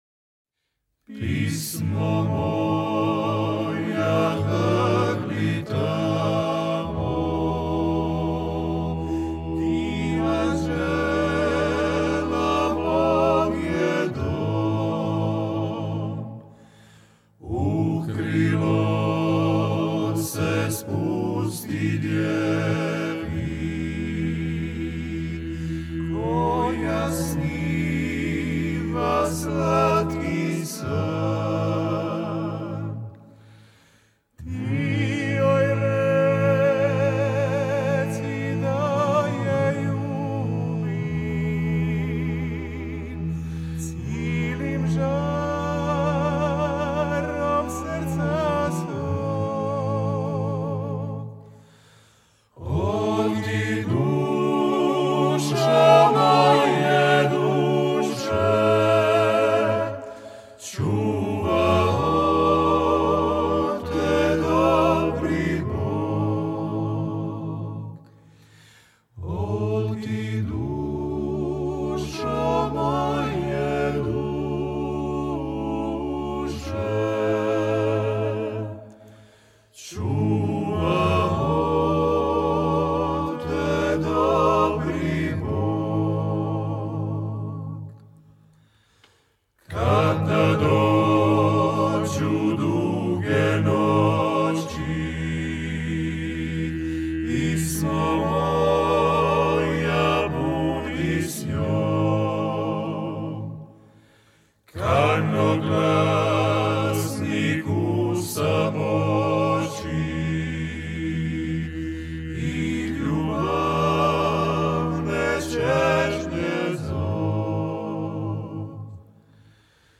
At other times, a cappella groups perform here and sell their CDs to tourists.